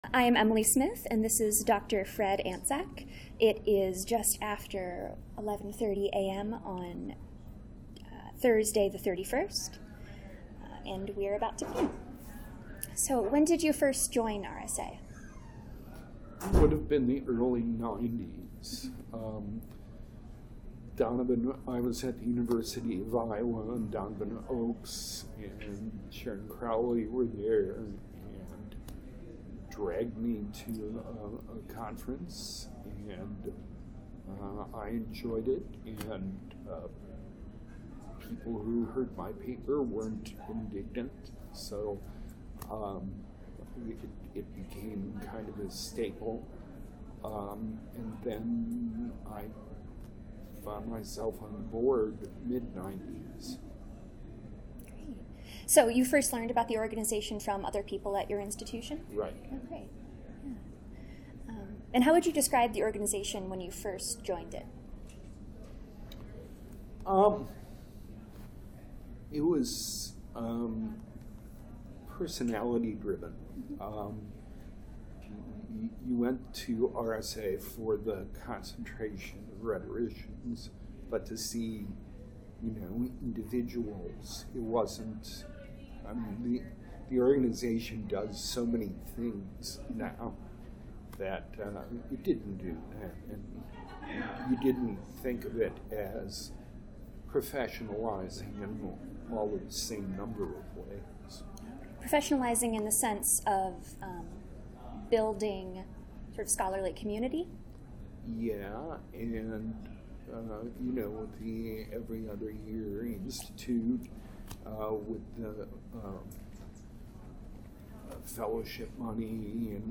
Oral History
Location 2018 RSA Conference